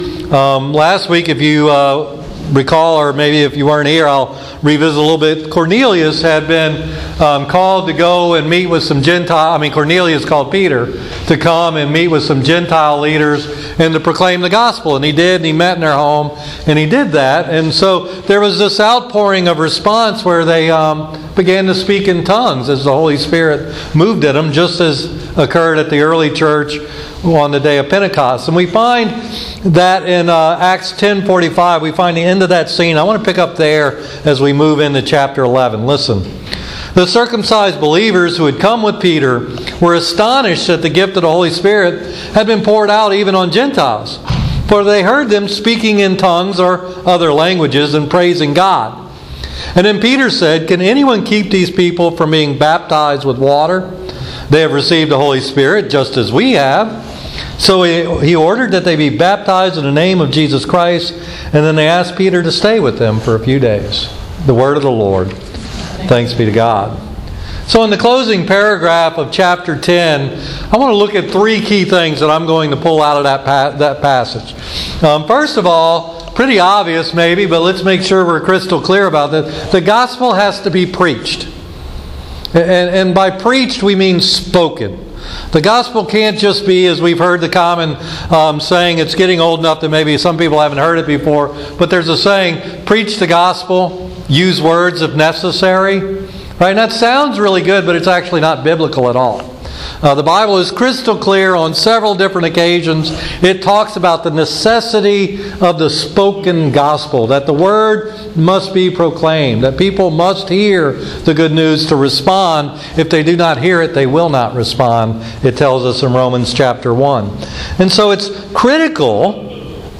Acts Chapter 11: A Sermon with No Title
acts-11-a-sermon-with-no-title.mp3